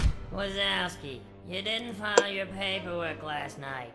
PLAY Mike Wazowski Scream